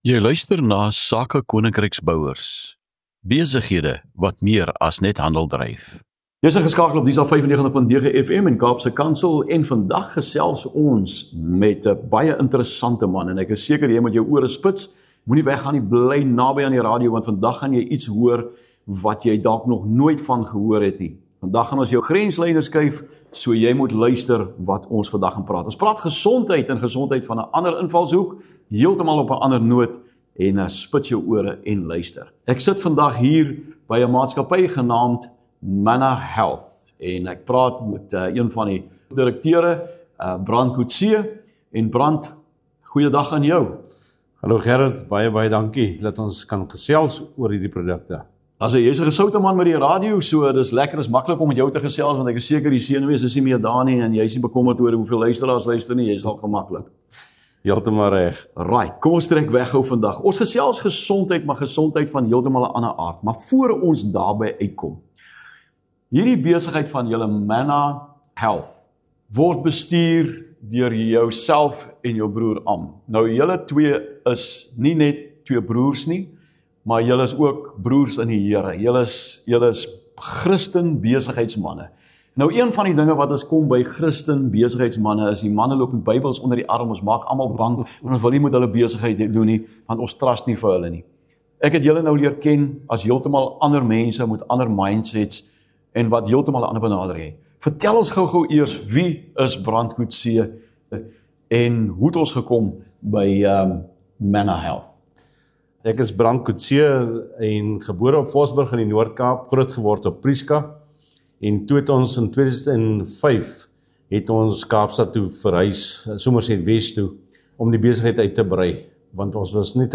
’n Inspirerende gesprek oor innovasie, integriteit en om ’n verskil te maak in Suid-Afrika en verder.